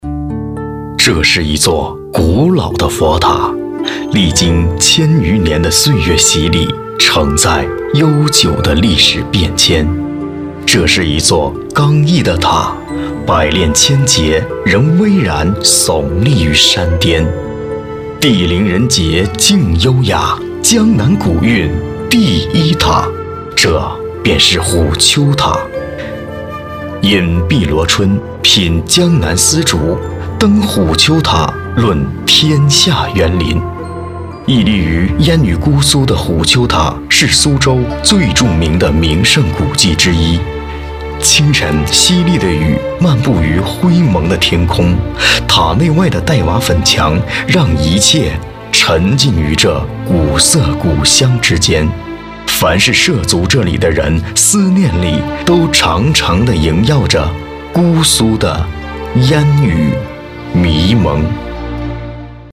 纪录片-男51-磁性-虎丘塔.mp3